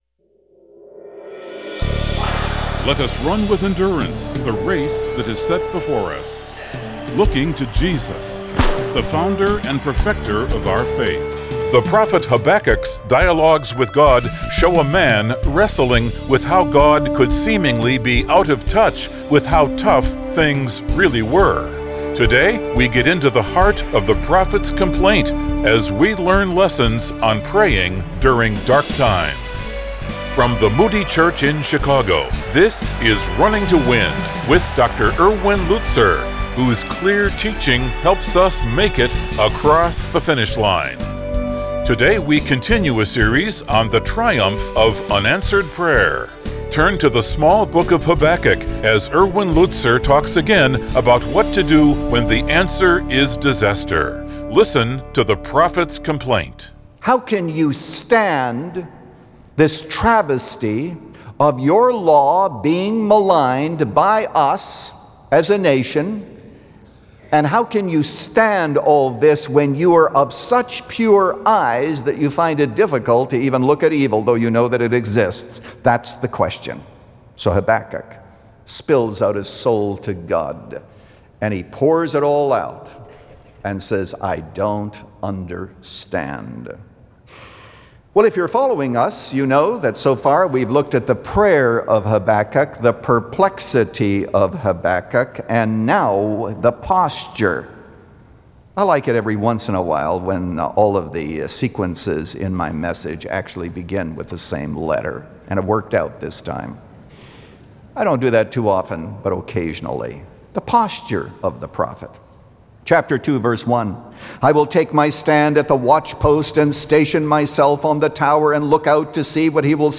When The Answer Is Disaster – Part 2 of 4 | Radio Programs | Running to Win - 15 Minutes | Moody Church Media